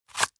magpouch_pull_small.ogg